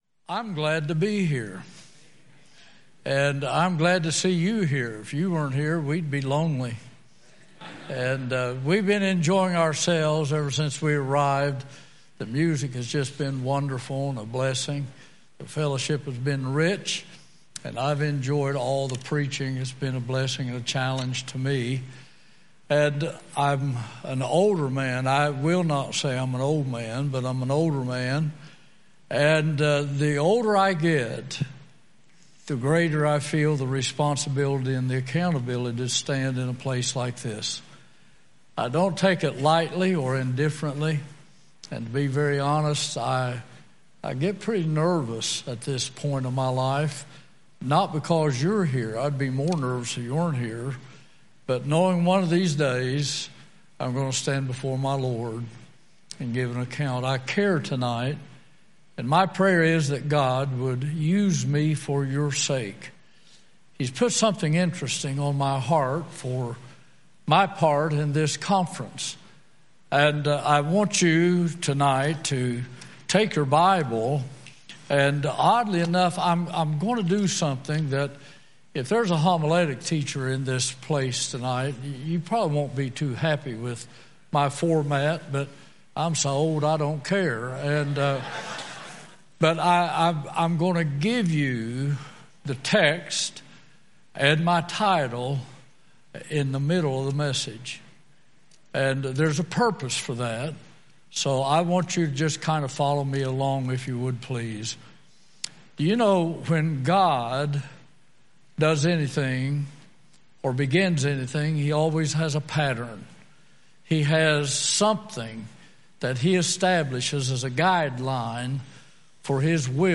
Wednesday Evening
Sermons